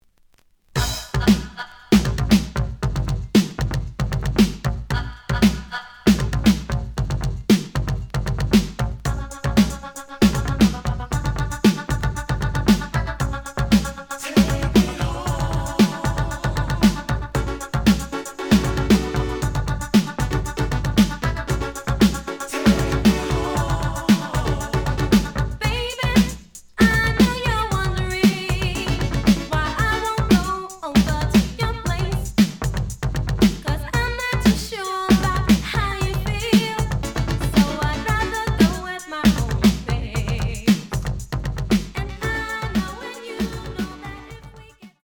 The audio sample is recorded from the actual item.
●Genre: Hip Hop / R&B
Slight edge warp. But doesn't affect playing. Plays good.)